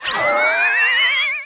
sniper_trail_02.wav